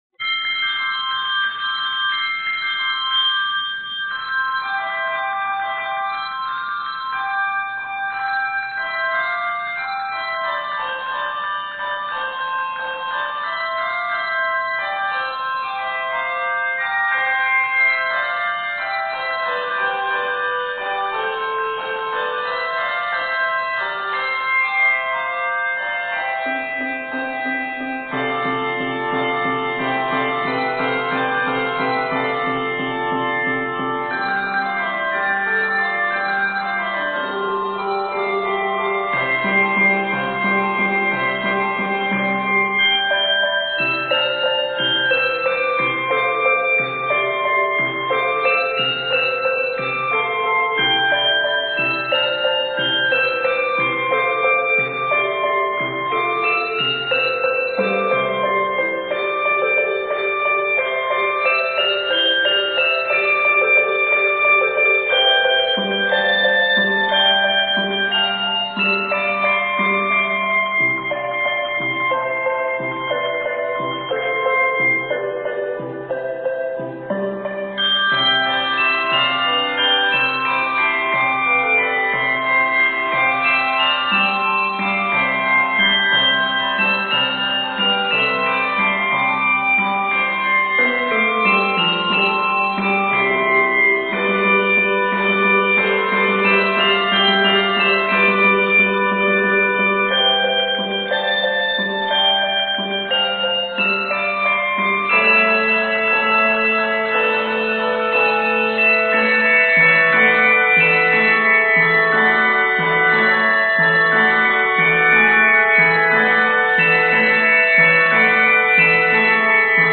Octaves: 3-5
2007 Season: Christmas